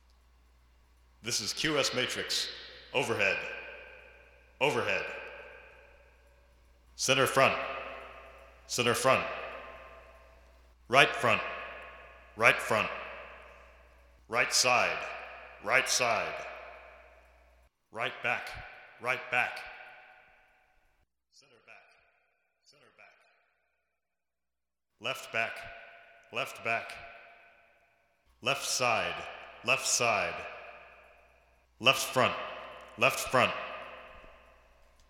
QS sample, channel identification